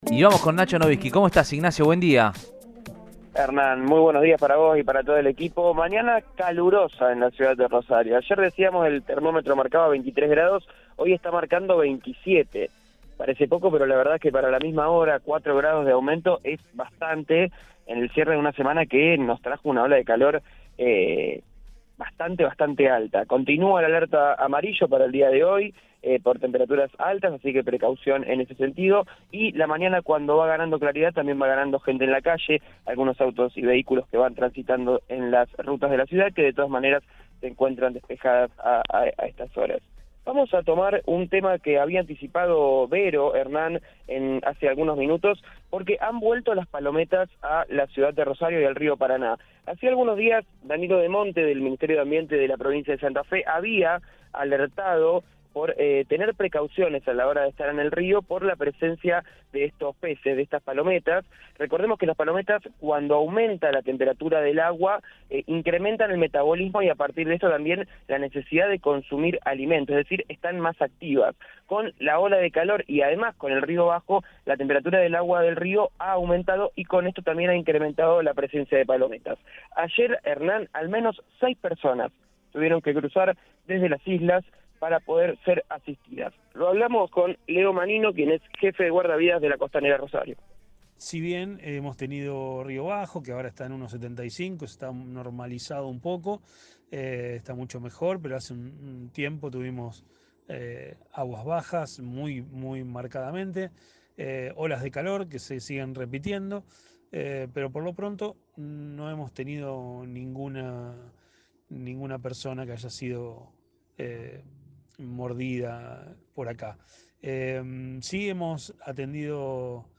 en diálogo con el móvil de Cadena 3 Rosario, en Radioinforme 3.